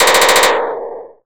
[EDIT] and ii just made a mechine gun Very Happy
Erm, I have to say, the only really usable one is gun burst 3, but all of them, it included, are too echoy and distant, if you get what I'm saying.
ux2_gun_110.wav